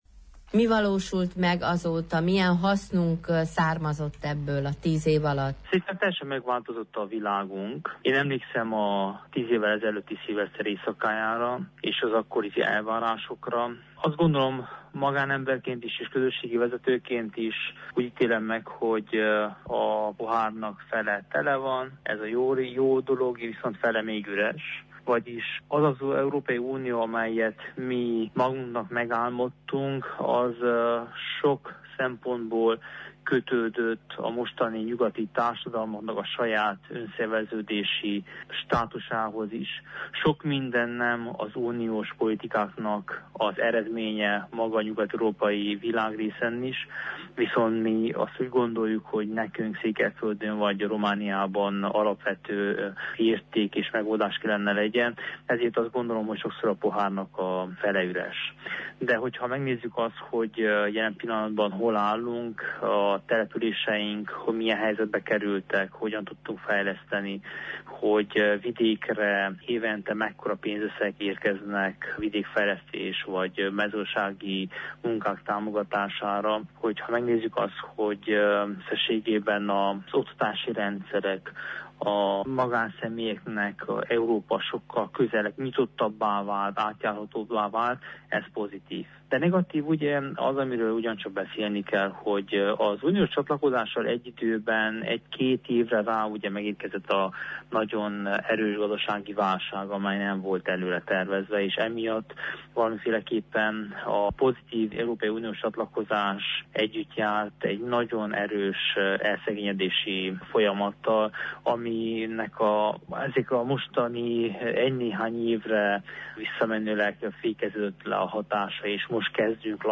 Milyen előrelépést jelentett Romániának a csatlakozás? – ezt Korodi Attila parlamenti képviselő, az európai ügyekért felelős bizottság tagja elemzi.